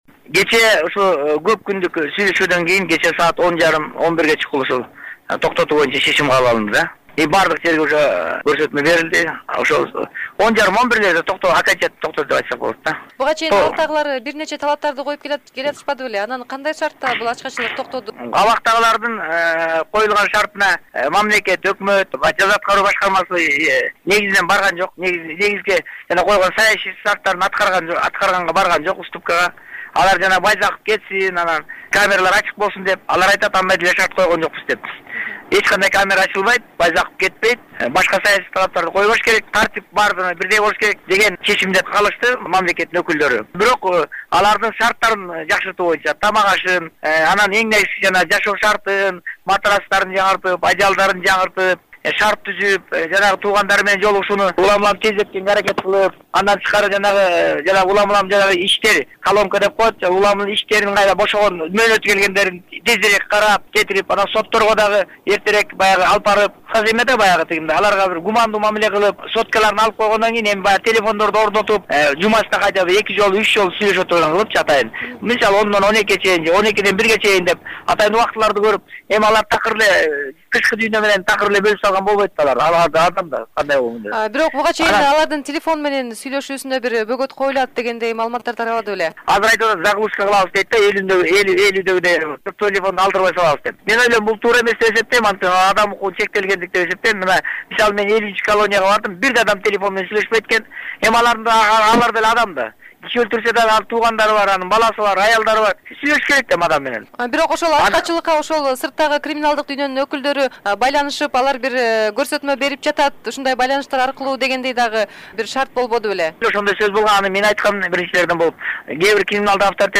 Турсунбек Акун менен маек